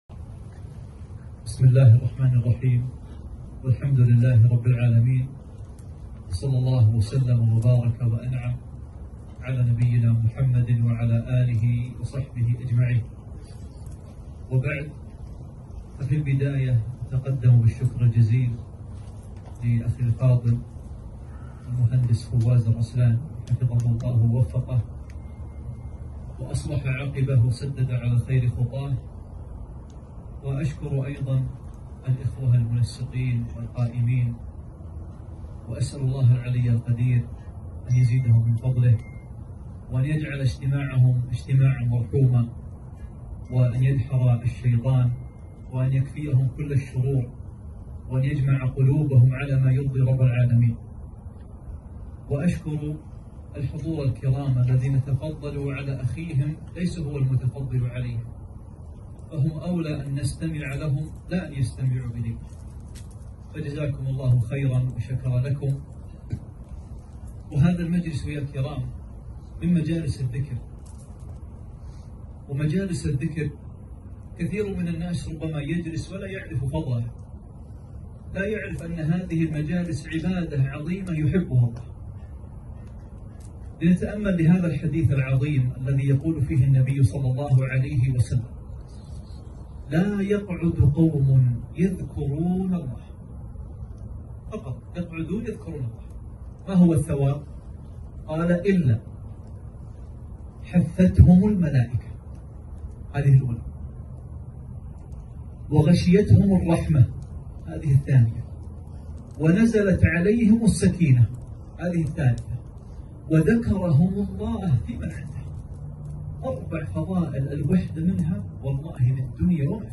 كلمة - ( أفلا أكون عبداً شكوراً )